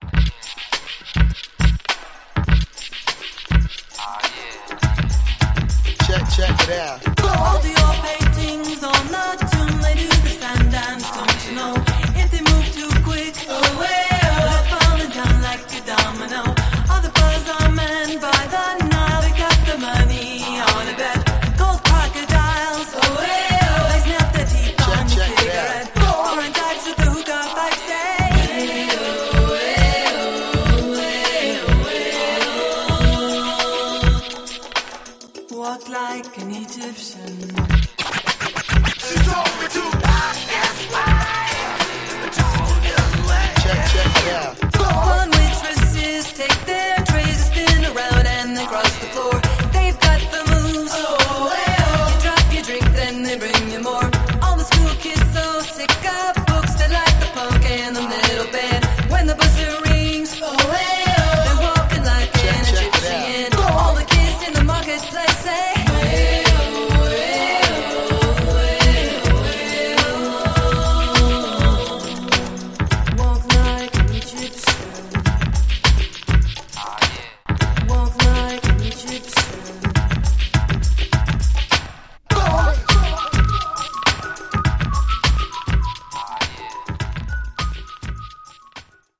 102 bpm
Clean Version